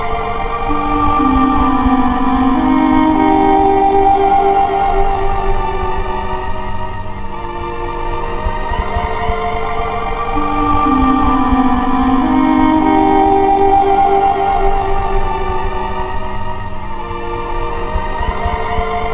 mysterydoor.wav